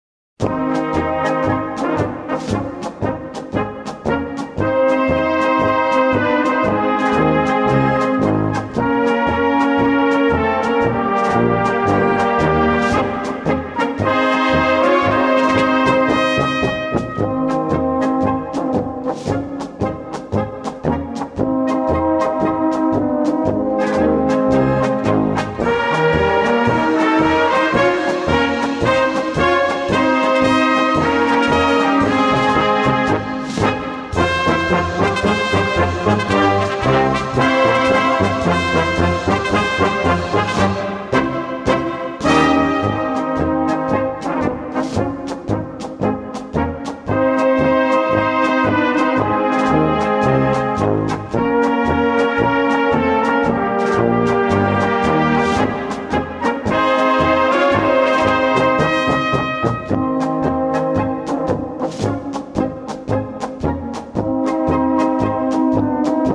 Gattung: Polka
Besetzung: Blasorchester
Enthält Text bzw. Gesangsstimmen.